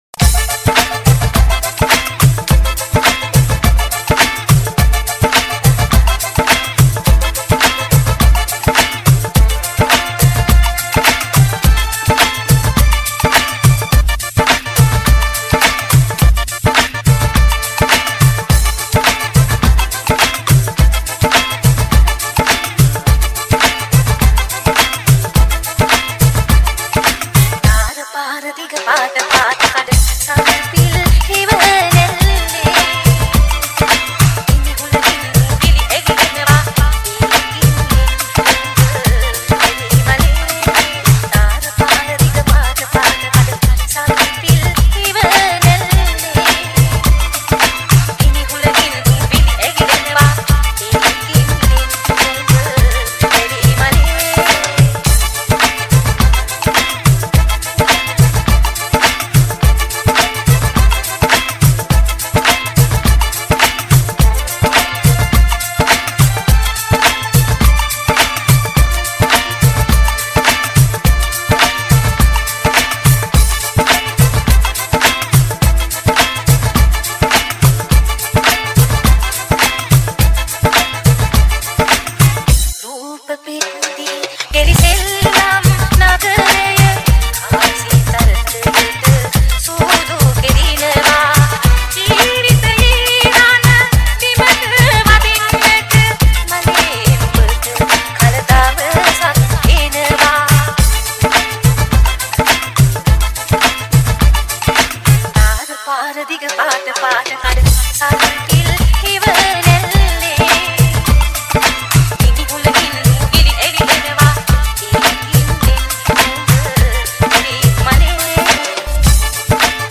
Sinhala Remix New Song